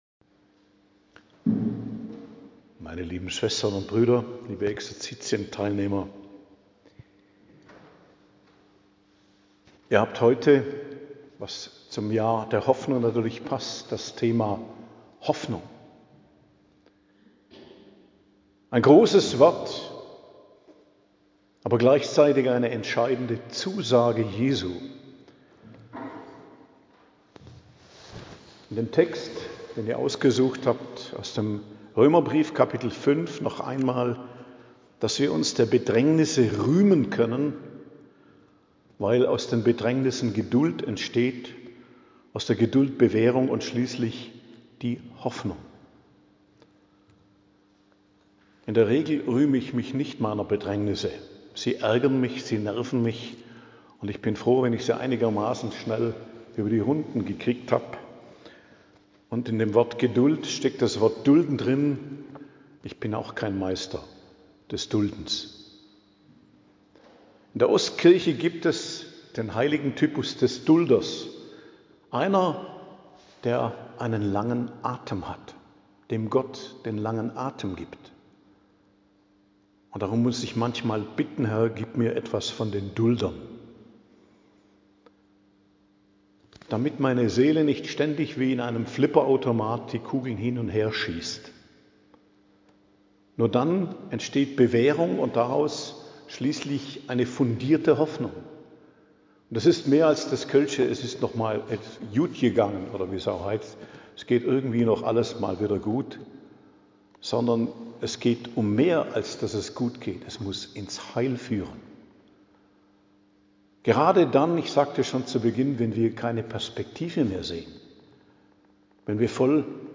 Predigt am Dienstag der 21. Woche i.J., 26.08.2025